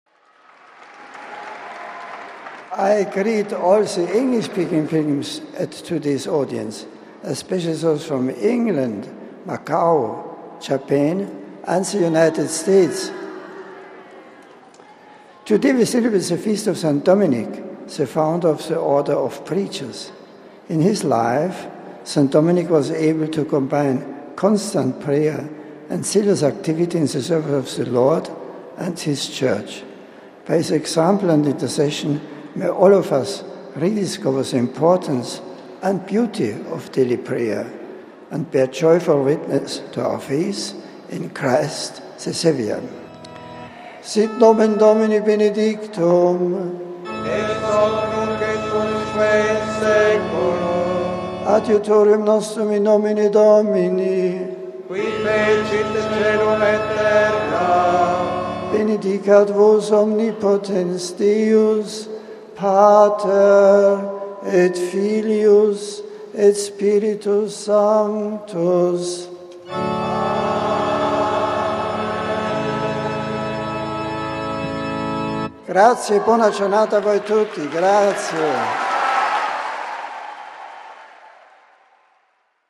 The general audience of August 8th took place in the courtyard of the summer papal residence in the hill town of Castel Gandolfo, just southeast of Rome, where the Pope is currently staying. Continuing his series of general audience reflections on prayer, Pope Benedict XVI this weekly focussed on the spirituality of the saint of the day - St. Dominic, the founder of the Order of Preachers or Domenicans, who lived between the 12th and 13th centuries.
Later during the general audience Pope Benedict also spoke in English.